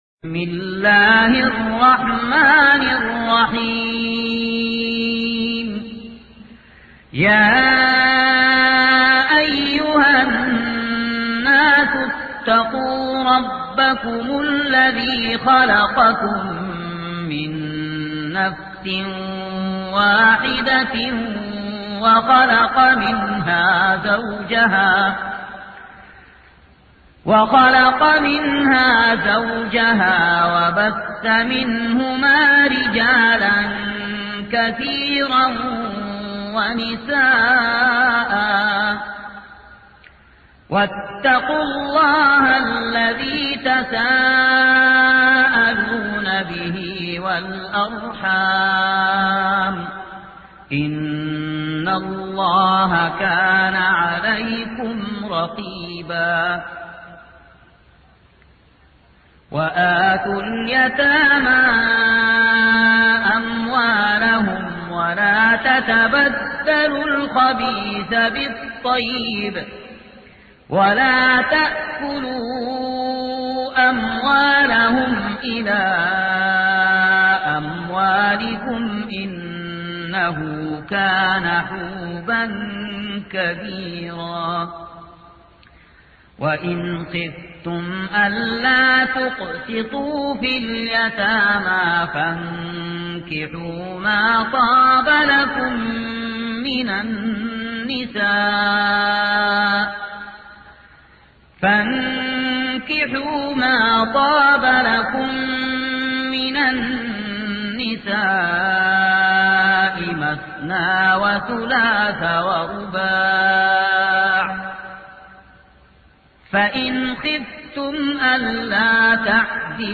سورة النساء | القارئ